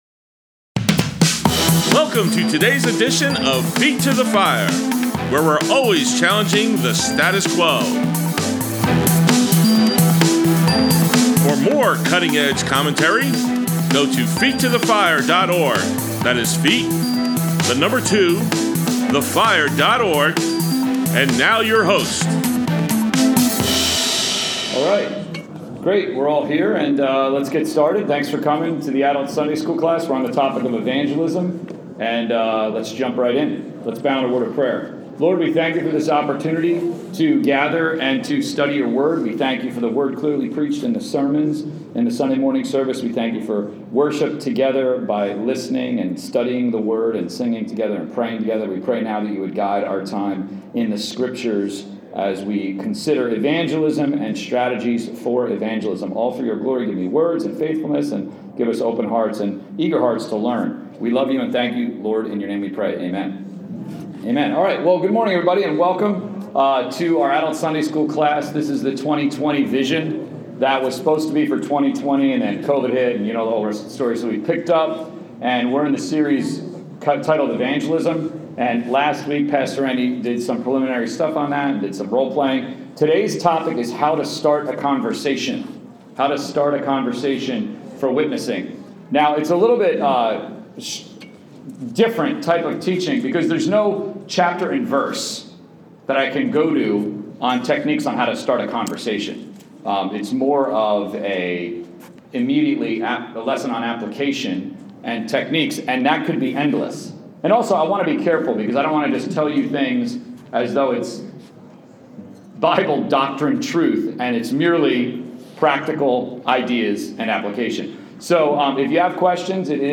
Adult Sunday School, Grace Bible Church, 1.9.22